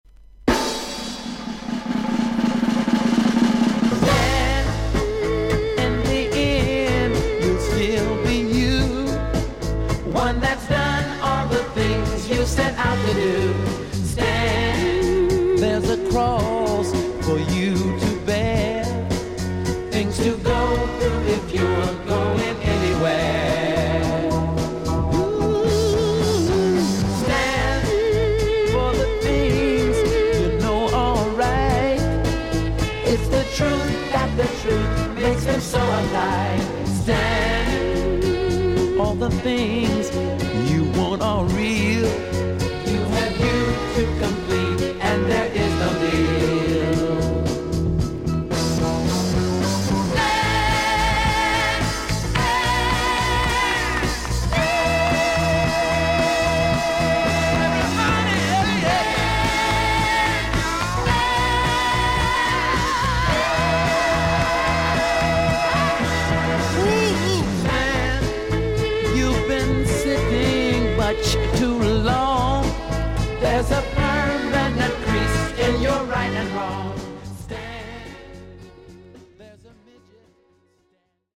少々軽いパチノイズの箇所あり。クリアな音です。